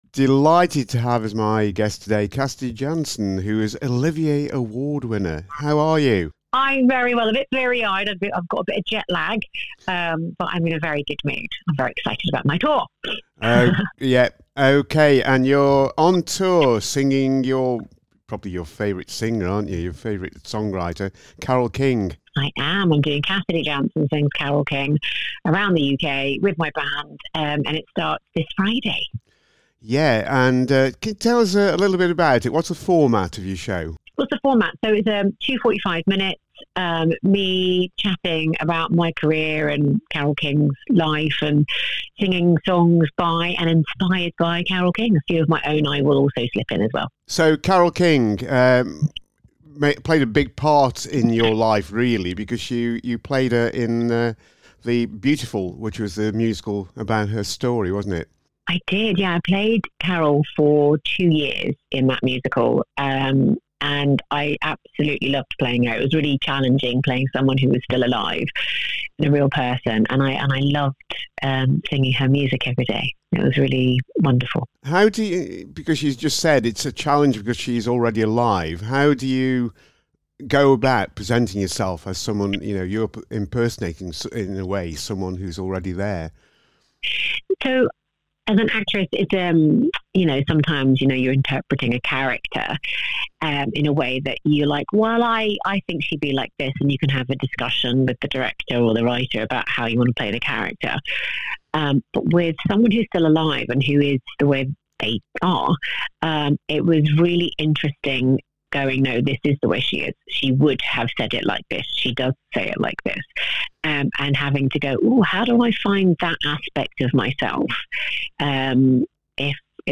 In the latest of our series highlighting the virtues of tribute acts I get to chat to Cassidy Jansen who performs the music of Carole King.